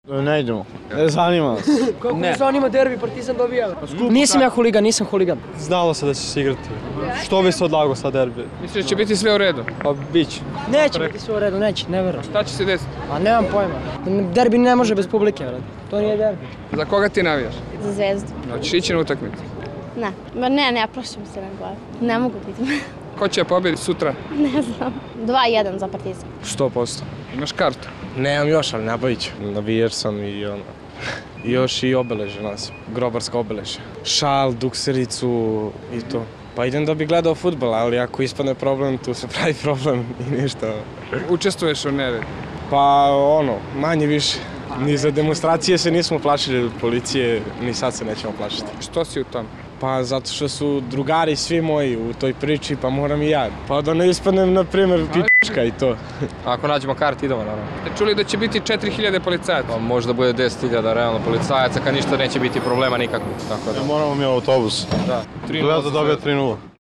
Anketa: Beograđani o derbiju Crvena zvezda-Partizan